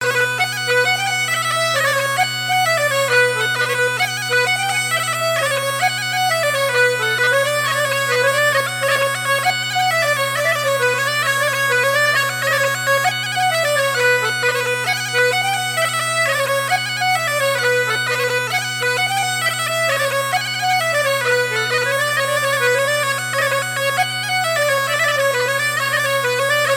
danse : branle
Pièce musicale éditée